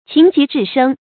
情急智生 注音： ㄑㄧㄥˊ ㄐㄧˊ ㄓㄧˋ ㄕㄥ 讀音讀法： 意思解釋： 情況緊急時；突然想出了解決問題的好辦法。